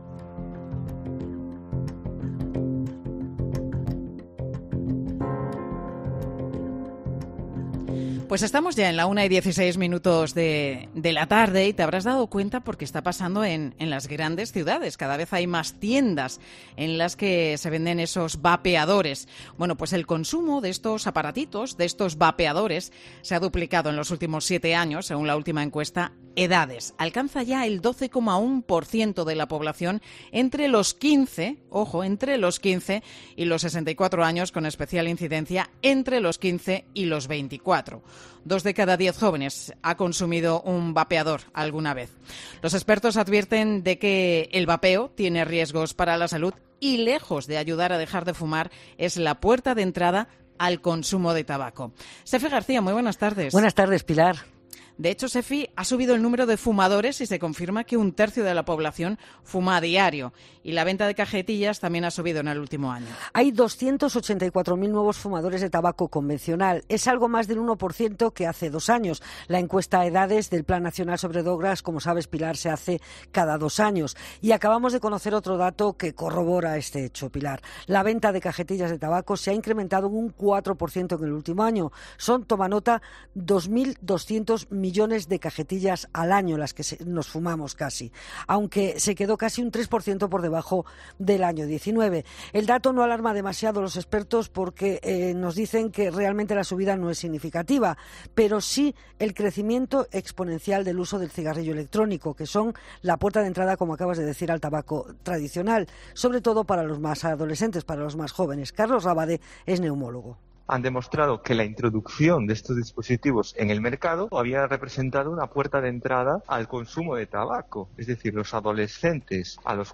¿Qué está pasando con el consumo de cigarrillos electrónicos entre los jóvenes? Análisis en Mediodía COPE